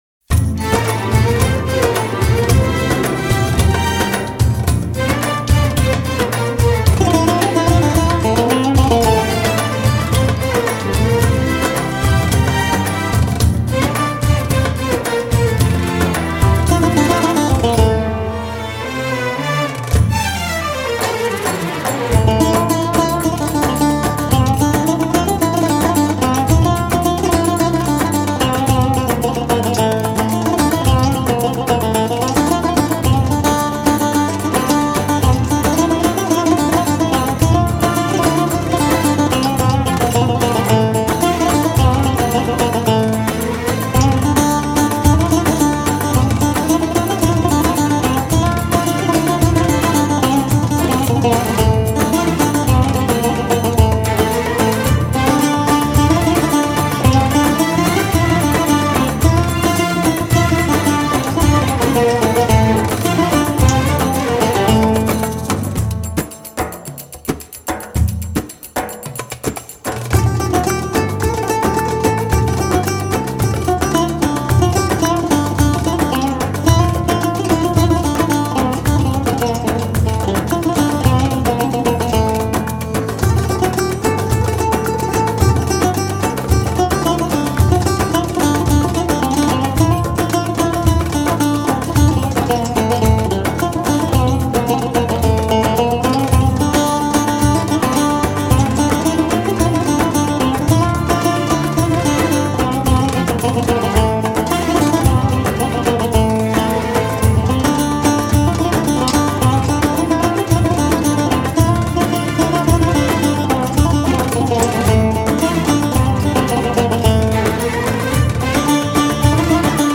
اثری زیبا و شاد
[نوع آهنگ: لایت]